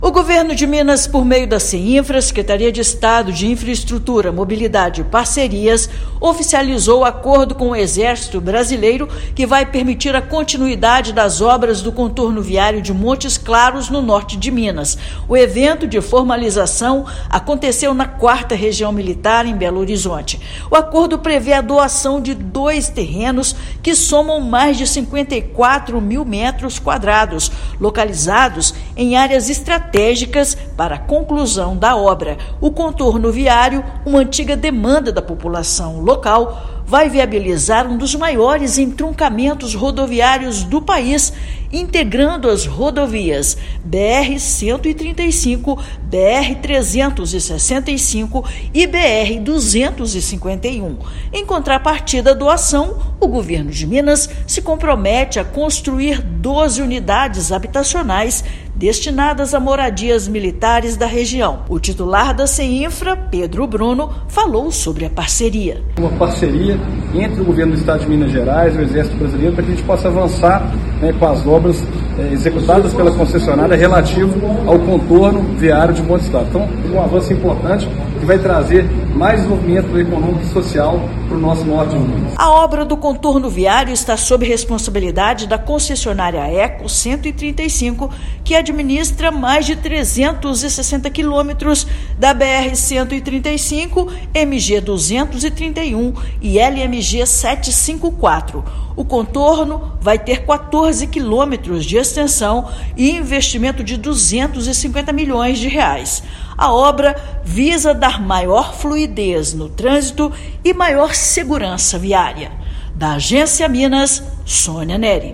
[RÁDIO] Governo de Minas formaliza acordo para a conclusão do contorno viário de Montes Claros